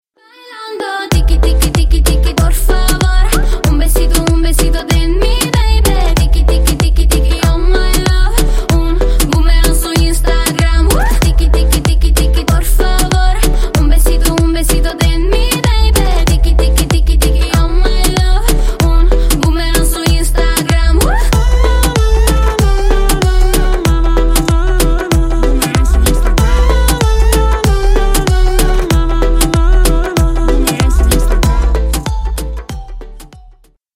Весёлые Рингтоны » # Латинские Рингтоны
Поп Рингтоны